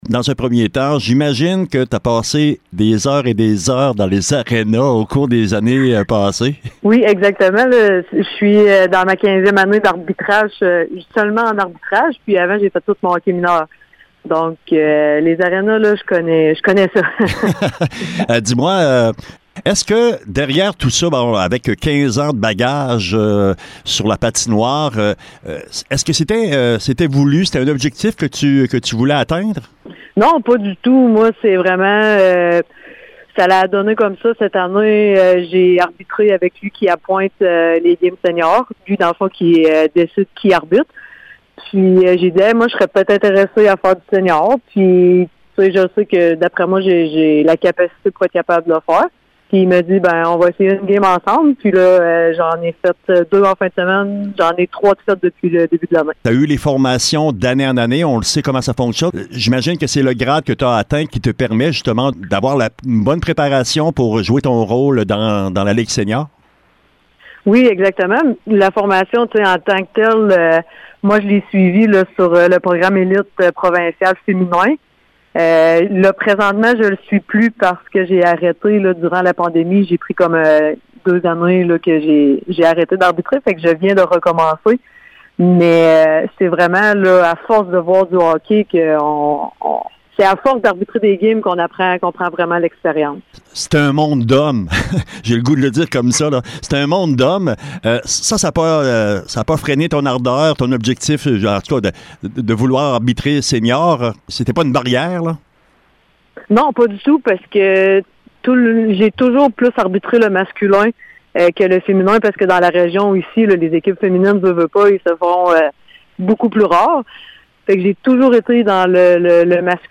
Entrevue intégrale